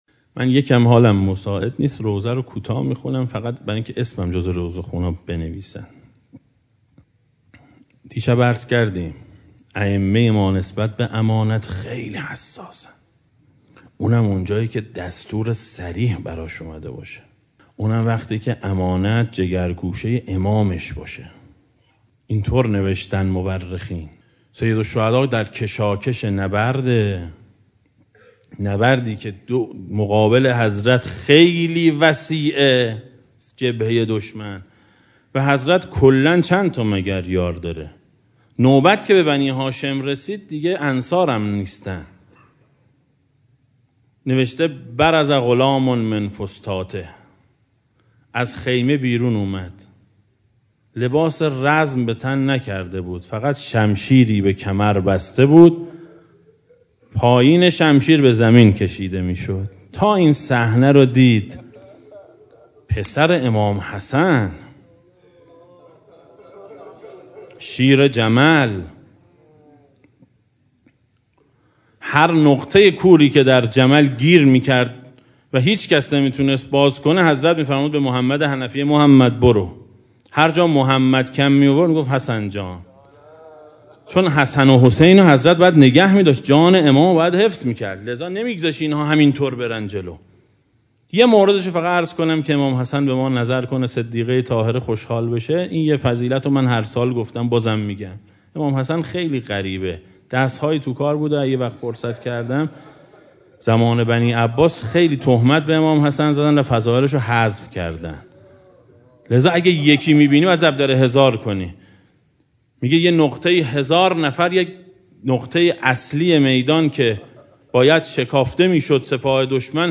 روضه شب ششم محرم سال 1395 ـ مجلس دوم